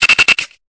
Cri de Grainipiot dans Pokémon Épée et Bouclier.